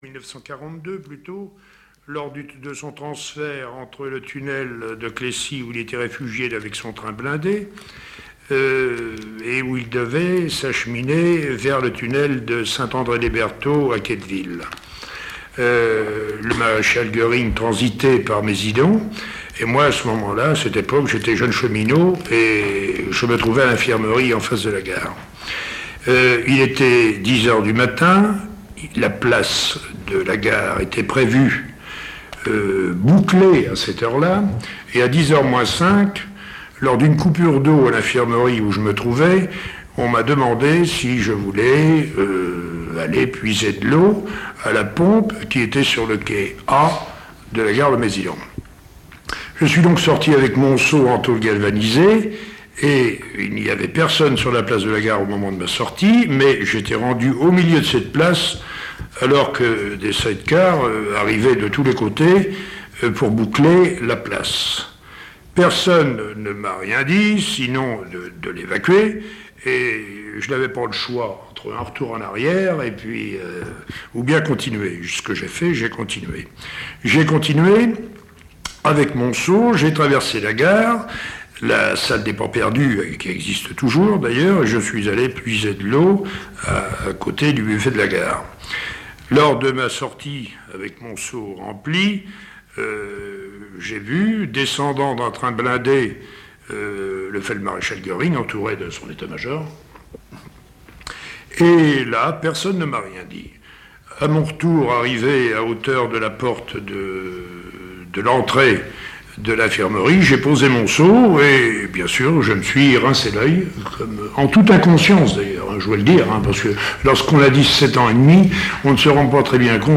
Cette interview est issue du fonds d'enregistrements de témoignages oraux relatifs à la Seconde Guerre mondiale conservés et archivés au Mémorial de Caen, que le musée a gracieusement mis à la disposition de la Maison de la Recherche en Sciences Humaines dans le cadre du projet de recherche Mémoires de Guerre.